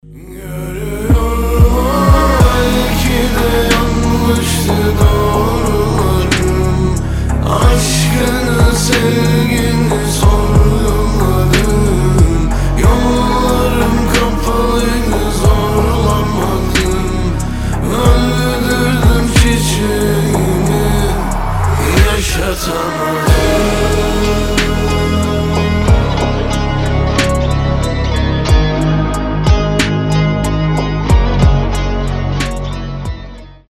• Качество: 320, Stereo
красивые
восточные
турецкие